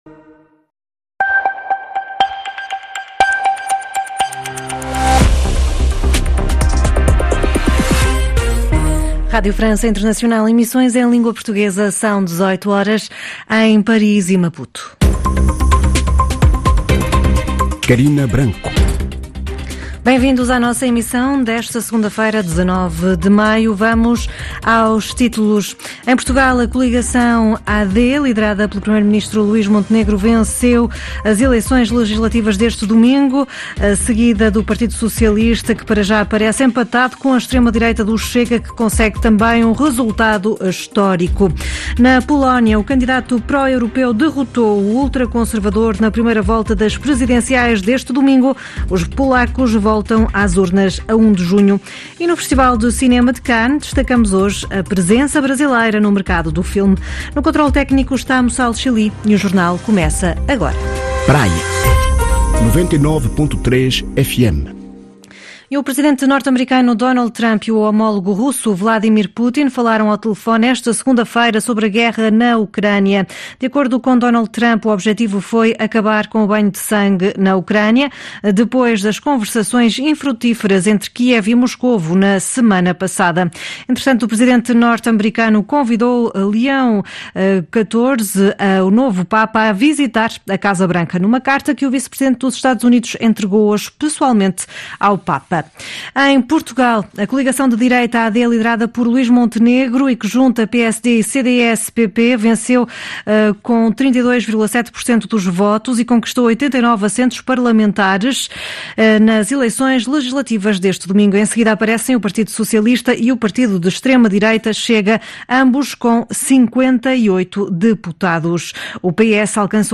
Sem a necessidade de instalar ou se inscrever Noticiário 19/05 16h00 TMG.
Ouça o jornal